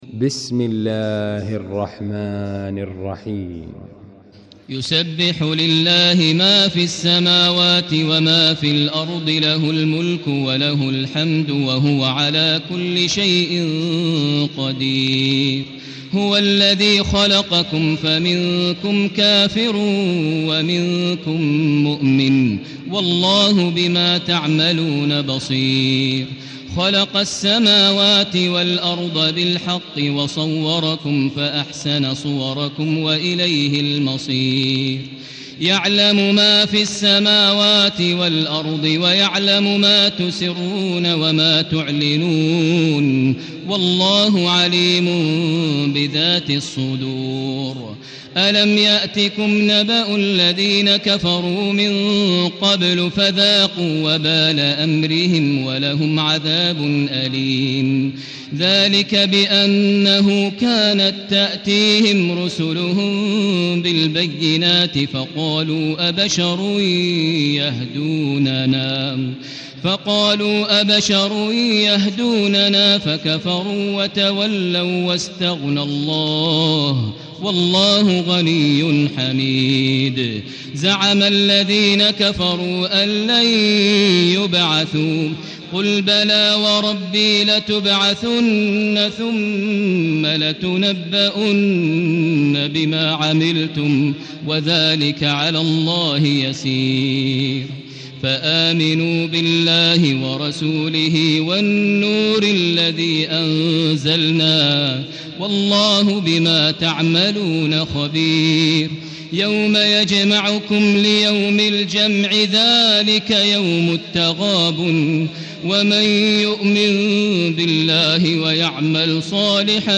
المكان: المسجد الحرام الشيخ: فضيلة الشيخ ماهر المعيقلي فضيلة الشيخ ماهر المعيقلي التغابن